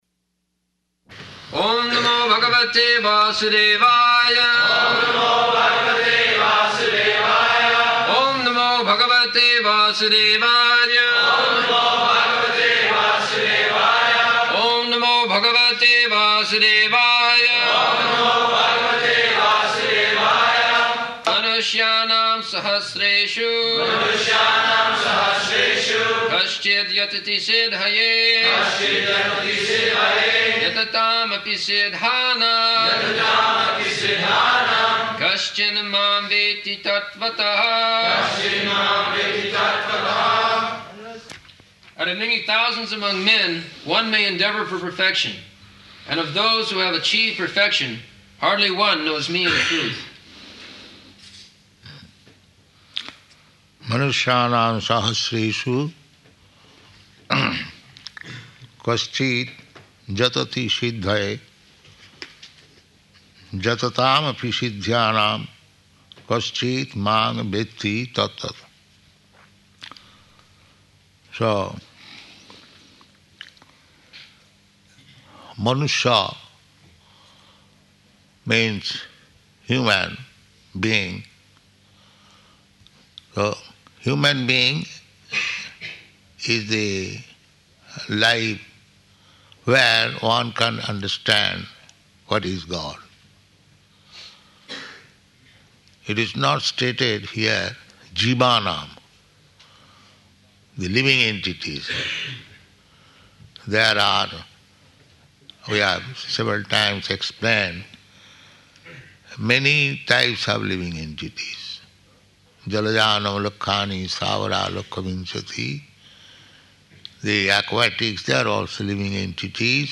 March 11th 1975 Location: London Audio file